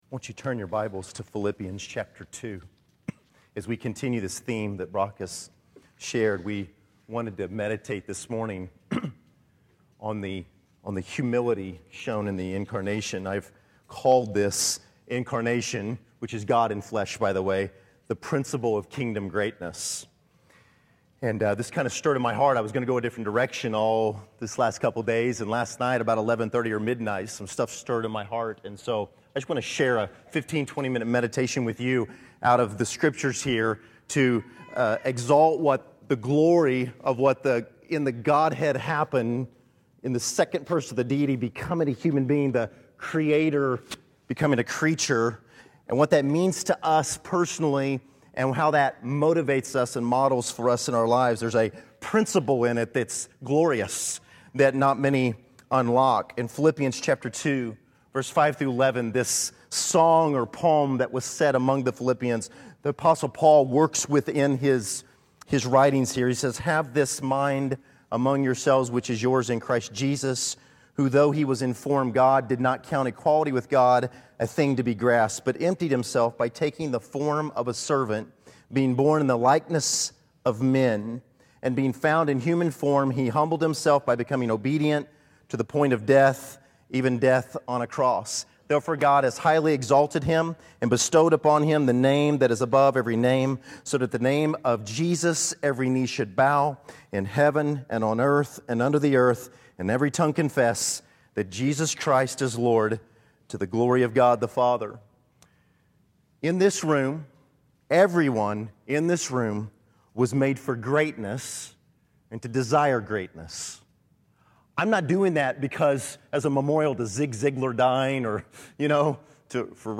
Incarnation December 23, 2012 Category: Sermons | Back to the Resource Library The principle of Kingdom greatness.